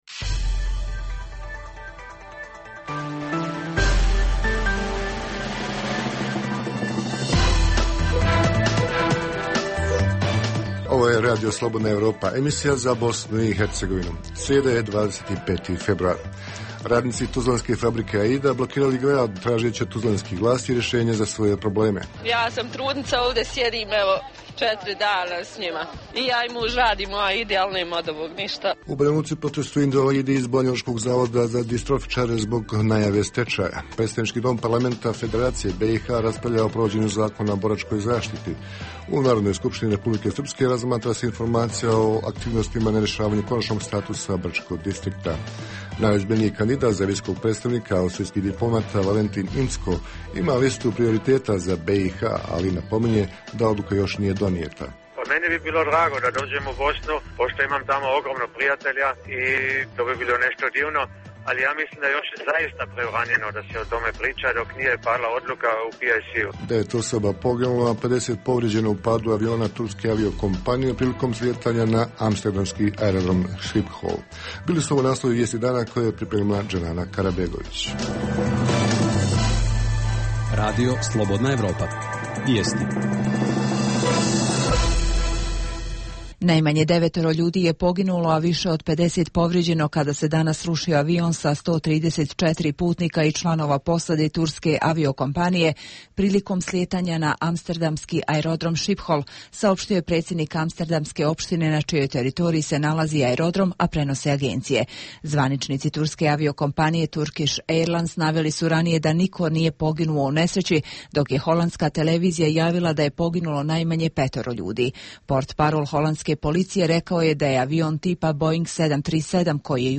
Emisija namijenjena slušaocima u Bosni i Hercegovini. U prvom planu protest radnika tuzlanske fabrike Aida koji su danas blokirali grad, zahtijevajući od vlasti rješanja za prevazilaženje teške situacije u fabrici kojoj prijeti stečaj i likvidacija. Najozbiljniji kandidat za visokog predstavnika u BiH Valentin Incko, austrijski diplomata, u razgovoru za naš program kaže da o tome ne želi pričati do konačne odluke, ali otkriva dobre odnose sa bh. ljudima.